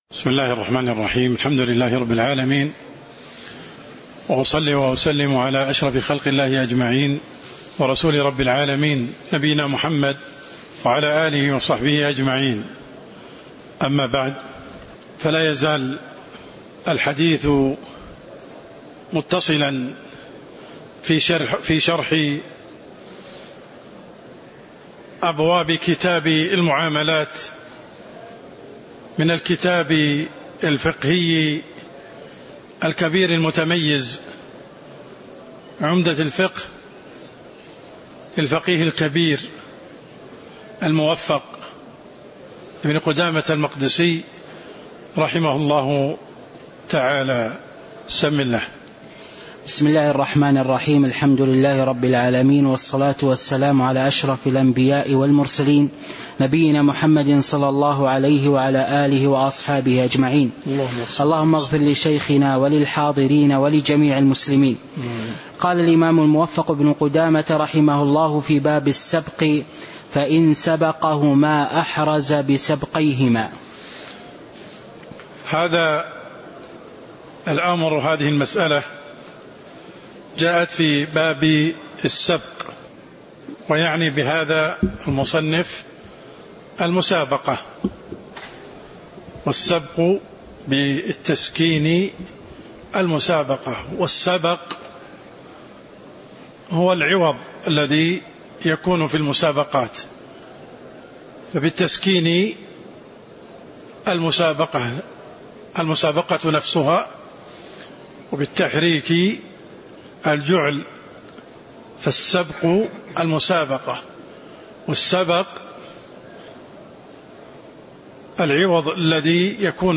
تاريخ النشر ٨ جمادى الآخرة ١٤٤٣ هـ المكان: المسجد النبوي الشيخ: عبدالرحمن السند عبدالرحمن السند باب السبق (011)